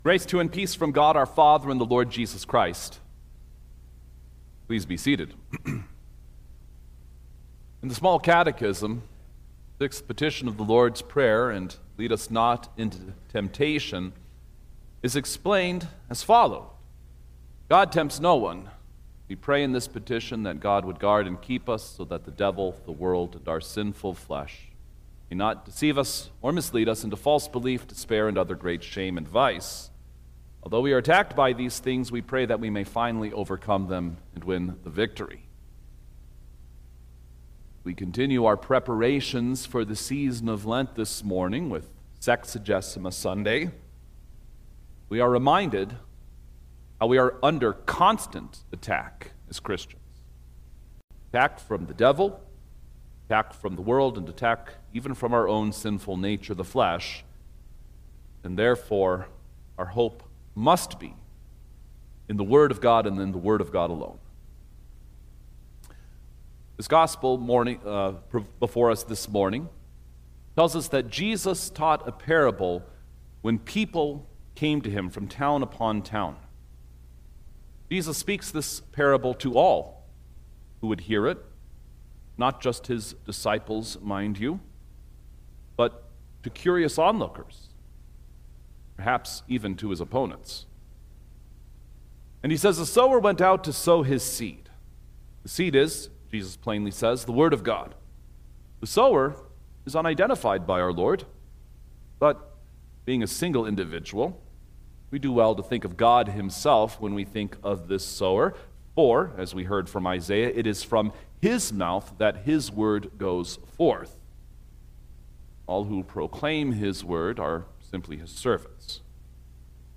February-8_2026_Sexagesima_Sermon-Stereo.mp3